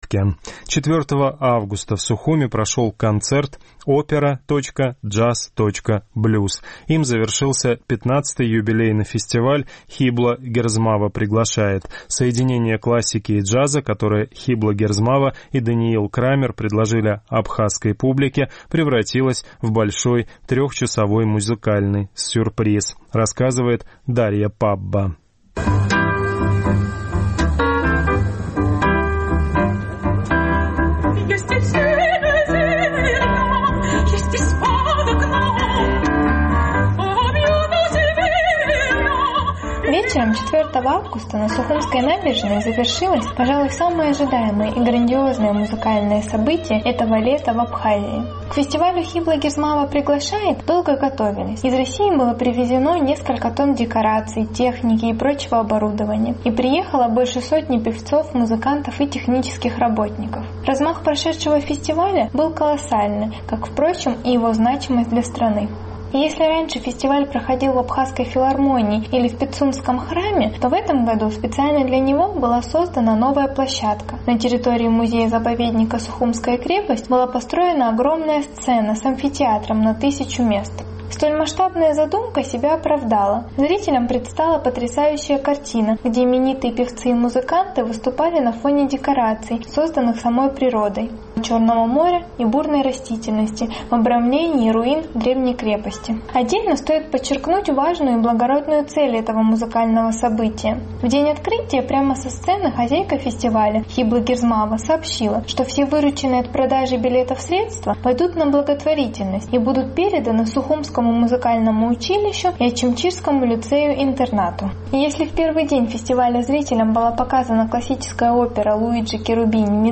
4 августа в столице Абхазии прошел концерт «Опера. Джаз. Блюз», им завершился XV юбилейный фестиваль «Хибла Герзмава приглашает…»
Вечером 4 августа на сухумской набережной завершилось, пожалуй, самое ожидаемое и грандиозное музыкальное событие этого лета в Абхазии.
Со сцены звучали то Моцарт, то Дунаевский, то Summertime, то Дворжак, то джаз-рок.